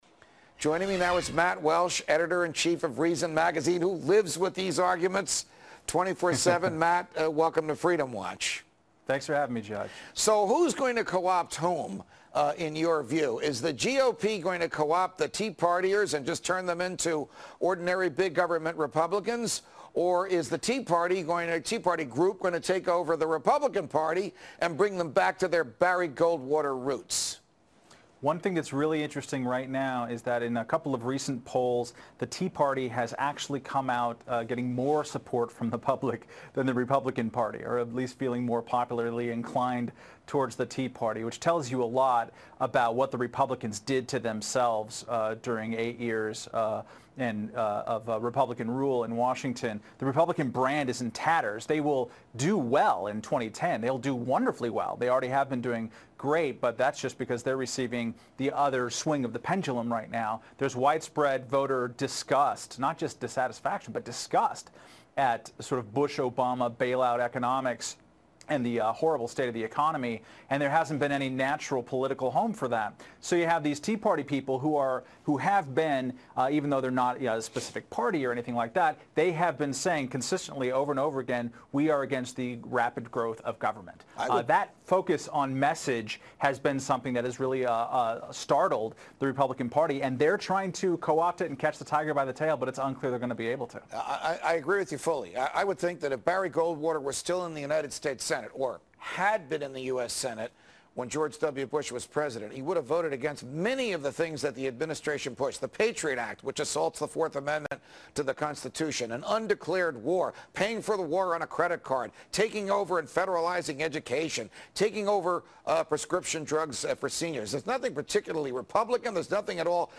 On Fox News' Freedom Watch, Judge Napolitano asks Reason Editor in Chief Matt Welch, if The Tea Party Movement is having an affect on The Republican Party on Feburary, 23 2010.